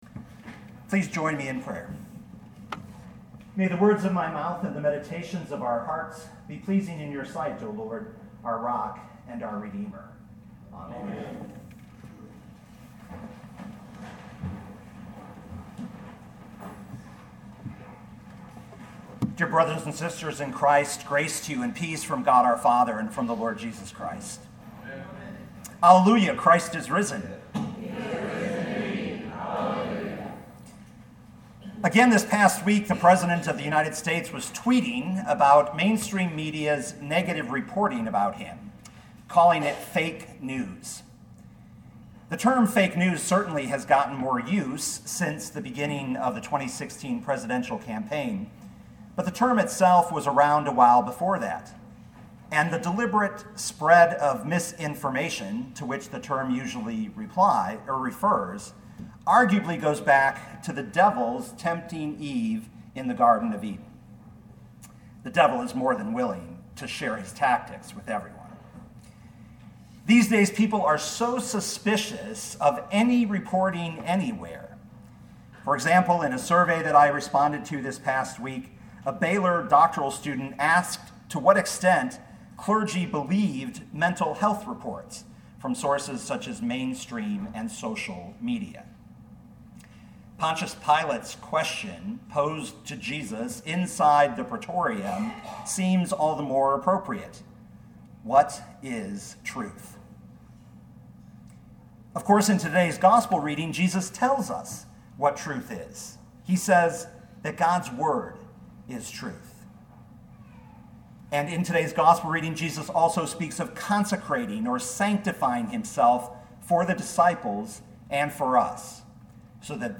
2018 John 17:11b-19 Listen to the sermon with the player below, or, download the audio.